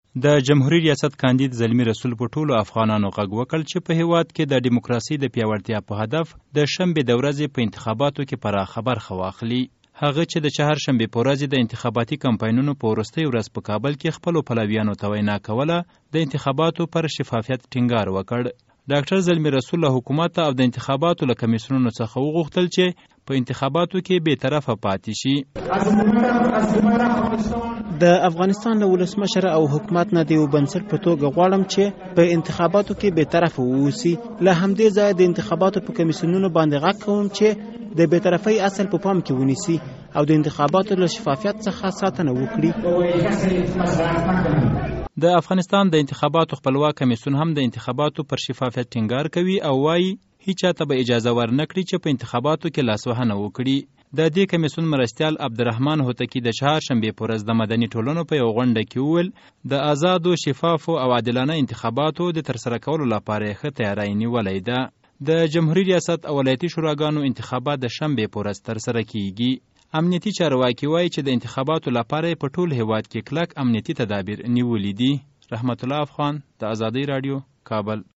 هغه چې د انتخاباتي کمپاینونو په وروستۍ ورځ یې خپلو پلویانو ته وینا کوله له حکومت او انتخاباتي کمېسیونونو هم وغوښتل چې په اتخاباتو کې بې طرفي پاتې شي.